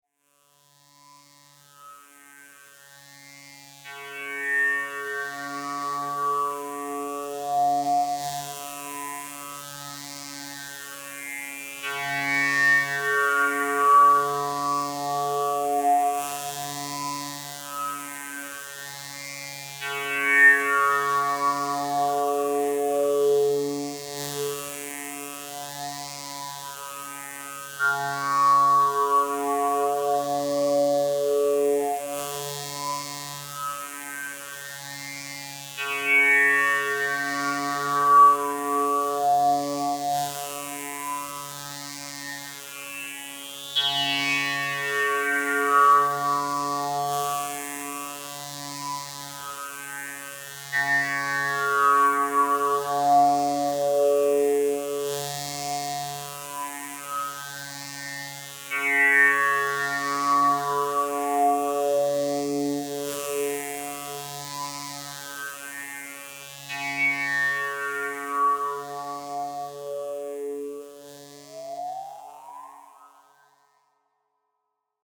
Hier ist erstmal ein Hörbeispiel, ein Drone-Sound, bei dem ich mit Obertönen herumexperimentiere. Dabei habe ich keinen externen Controller, kein Keyboard und auch keinen Sequencer benutzt, nur die Regler des Expanders, um die Sounds zu erzeugen (ein Tipp für das Anhören: Um die Obertöne möglichst alle zu hören, sollte man bei den Equalizer-Einstellungen die hohen Frequenzen so weit wie gesundheitlich verträglich aufdrehen): B2600_Obertöne